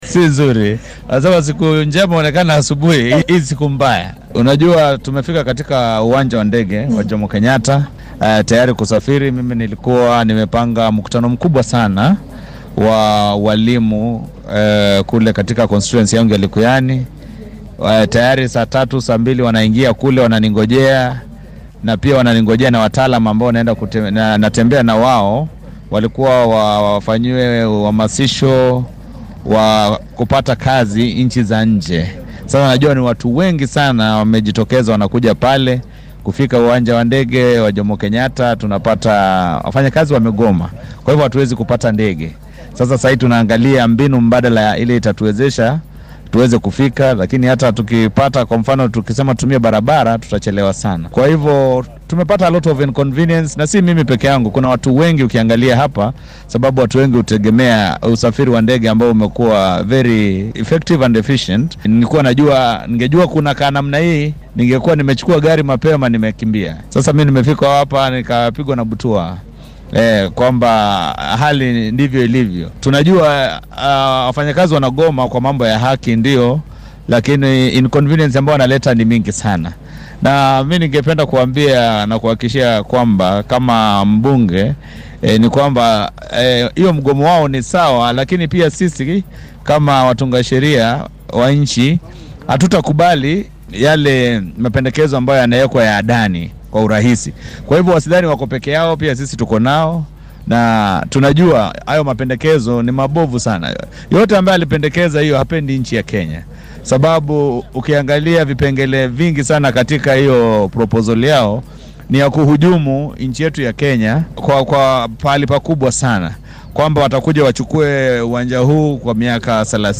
Mas’uuliyiinta ay shaqo joojinta shaqaalaha waaxda duullimaadka saameysay ee ku xayirmay garoonka JKIA waxaa ka mid ah xildhibaanka deegaanka Likuyani ee ismaamulka Kakamega ,Innocent Mugabe oo dareenkiisa la wadaagay warbaahinta.